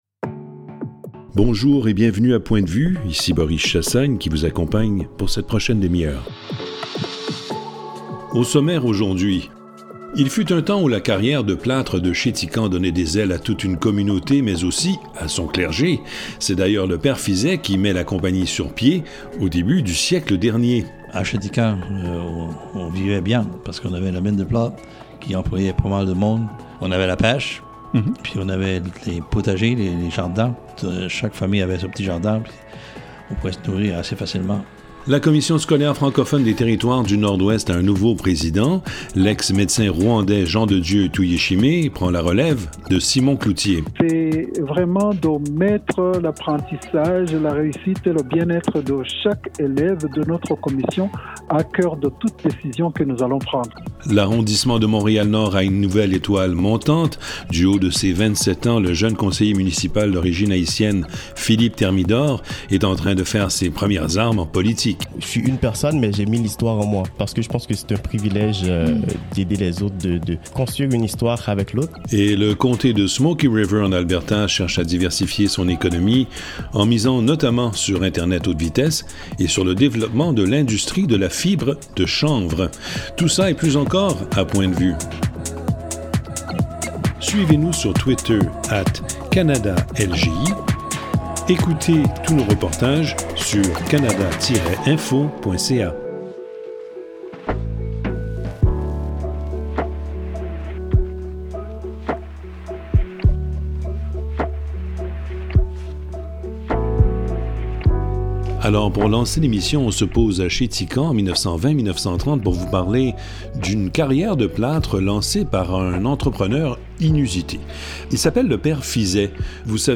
Émission Points de vue, Éd.2 Magazine «Points de vue», en route sur les ondes de 39 stations canadiennes.